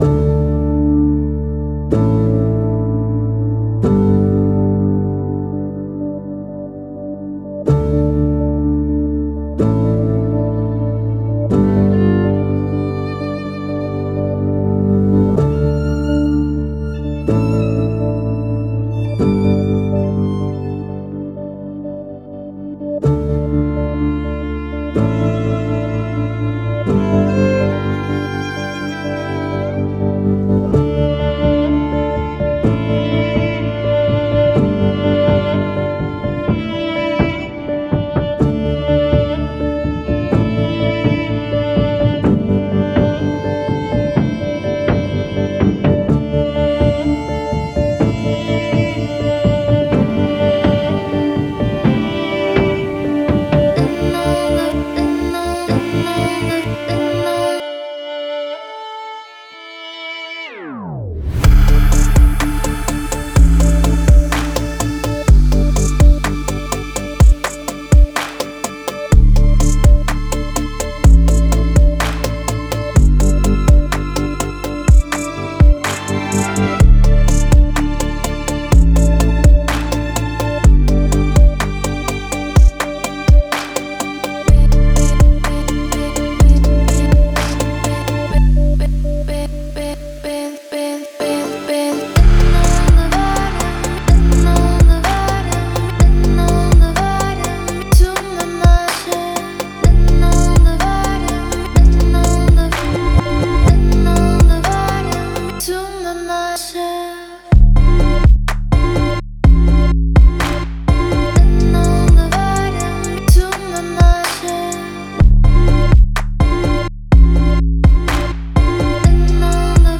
lead violin over synth pads